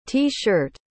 Como se pronuncia T-shirt?
A pronúncia em português ficaria com som de “tii shãrt”.